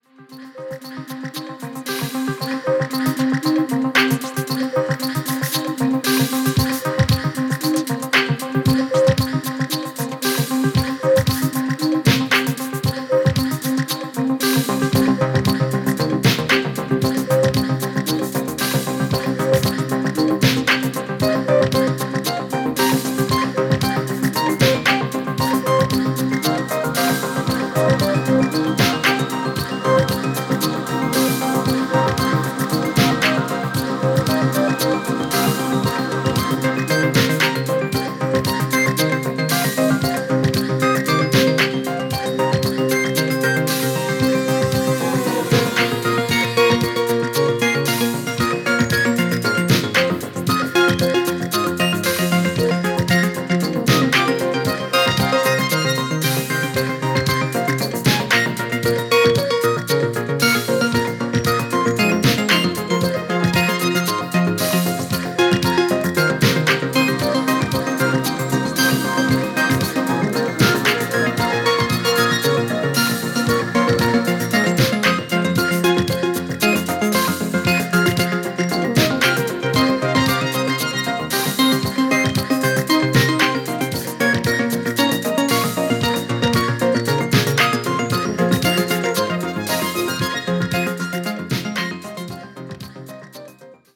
中国でのライブ2枚組です！！！